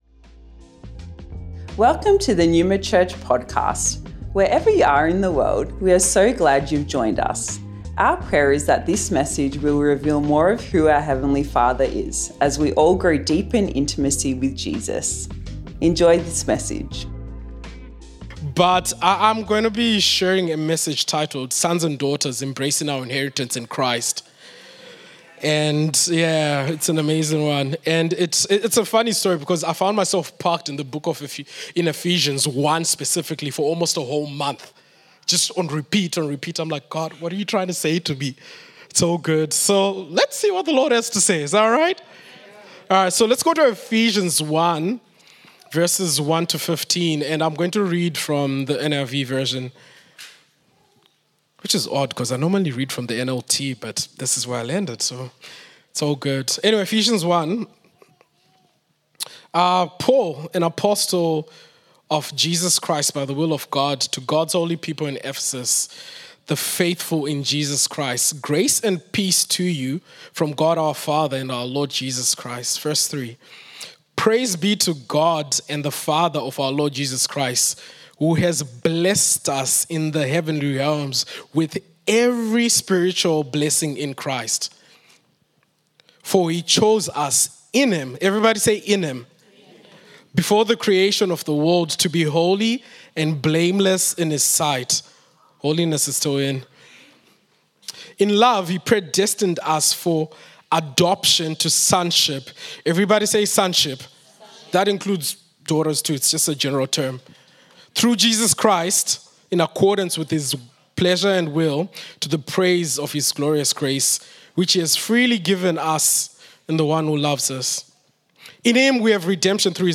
Originally recorded at Neuma Melbourne West